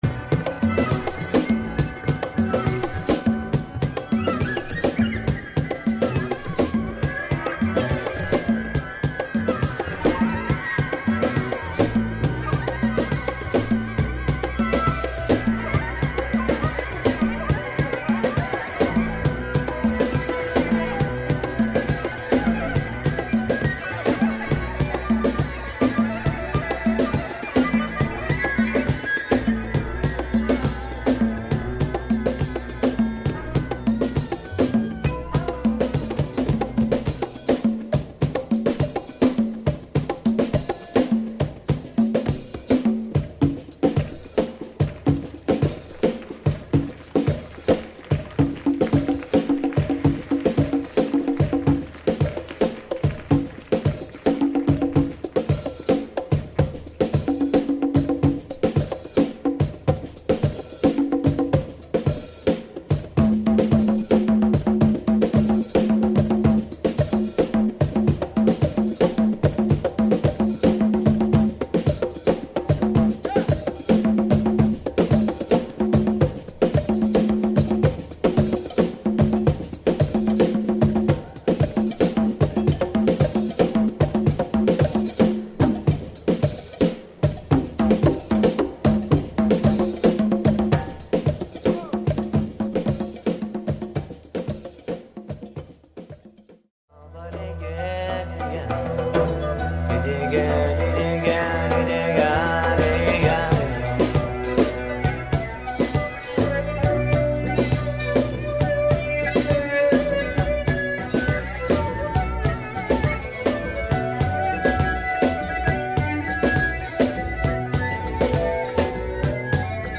A deep African feel.